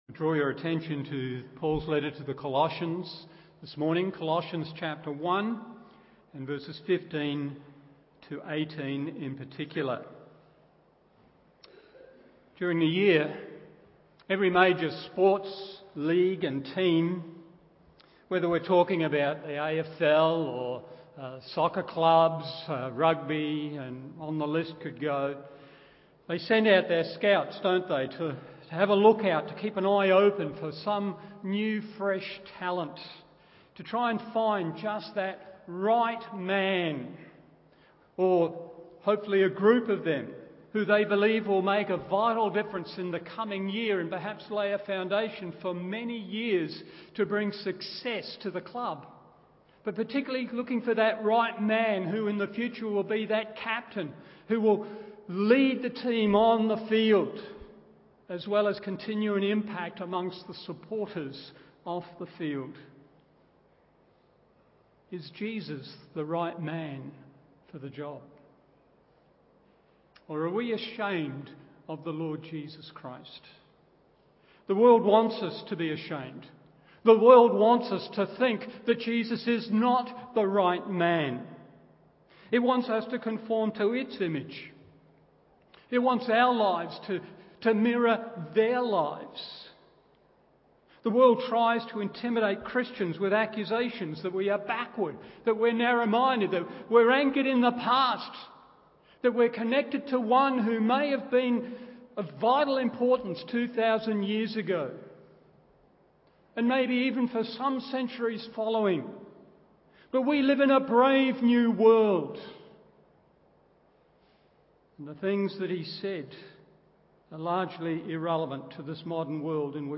Morning Service Colossians 1:15-18 1. His relationship to God 2. His relationship to the Creation 3. His relationship to the Church…